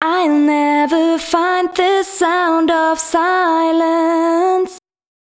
012 female.wav